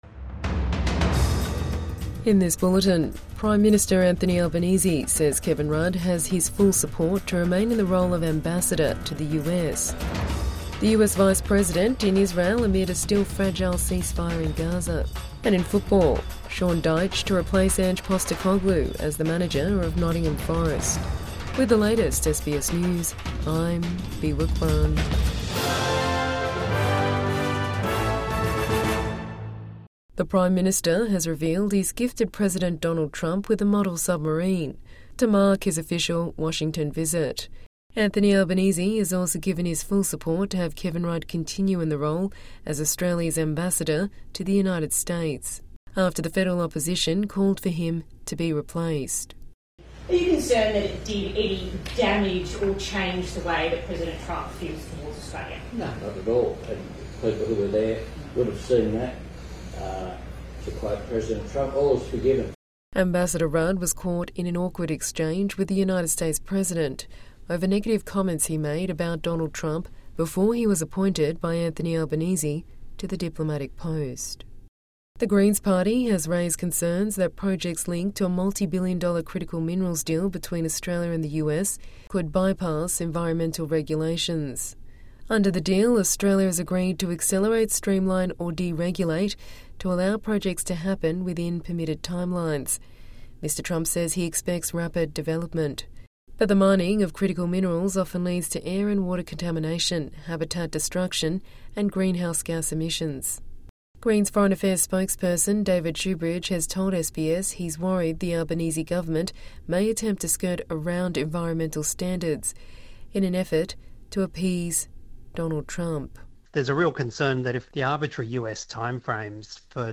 PM says Kevin Rudd has his backing to stay on as ambassador to the US | Morning News Bulletin 22 October 2025